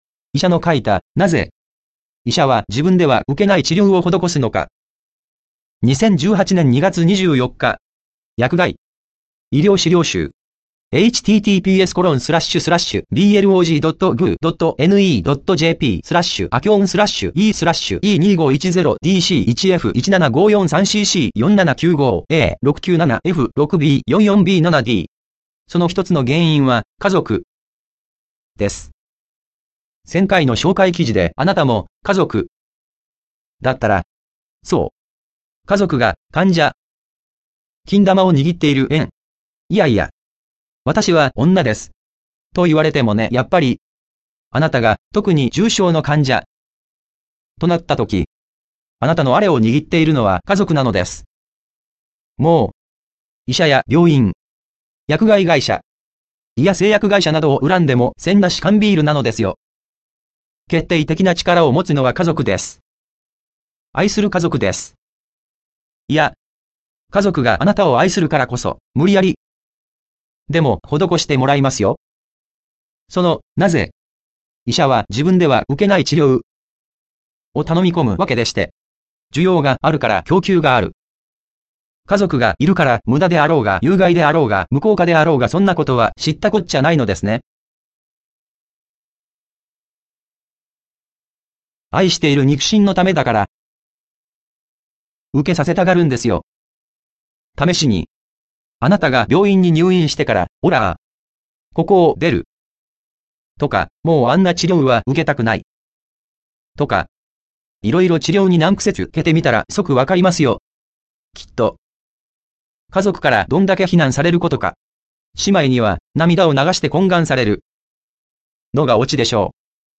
その「なぜ、医者は自分では受けない治療」を頼み込むわけでして・・・ 音声読み上げはこちら （いきなり読み出しますのでご注意） 需要があるから供給がある。